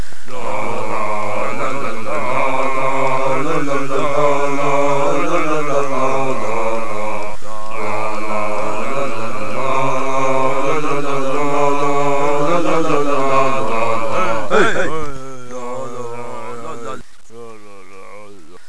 Wave files are sounds that you record with a microphone. The clicking noises in the background of some songs is my metronome.
This is a song called Hava Nagila. It is a Jewish dance from Poland. This song displays the capabilities of multitrack recording, along with being really funny.